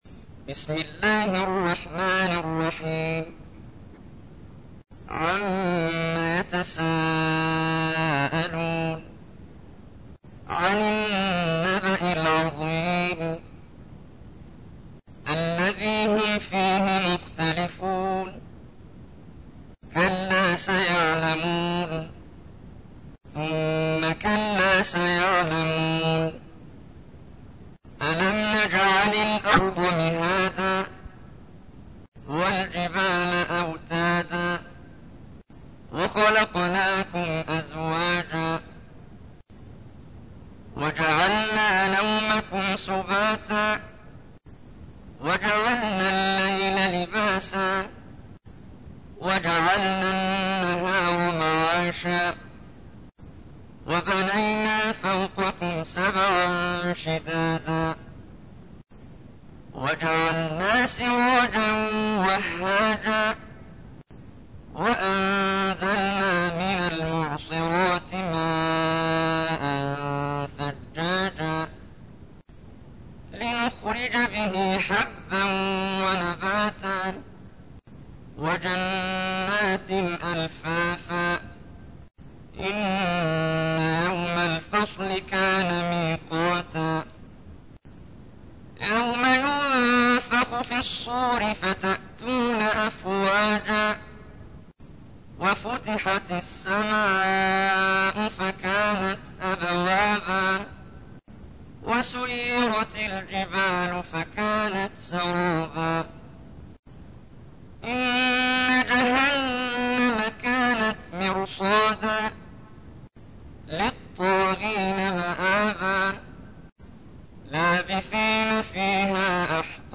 تحميل : 78. سورة النبأ / القارئ محمد جبريل / القرآن الكريم / موقع يا حسين